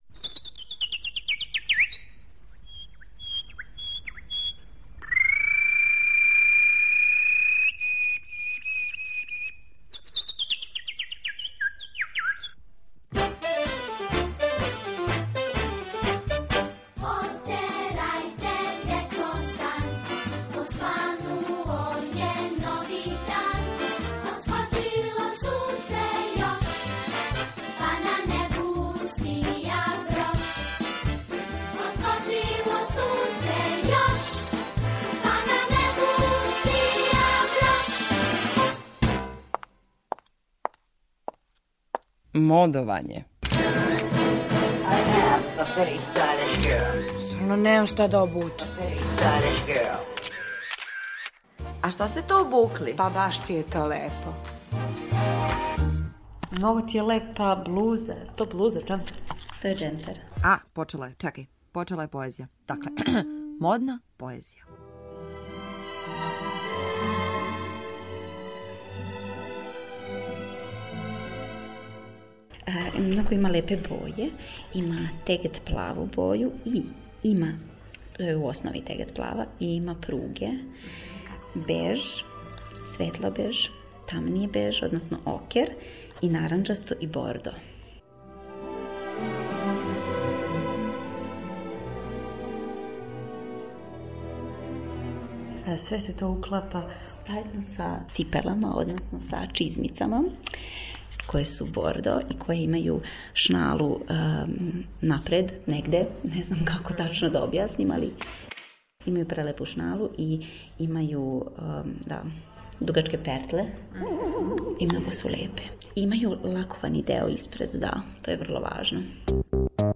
У серијалу о моди, питали смо случајну пролазницу да нам опише своју одевну комбинацију.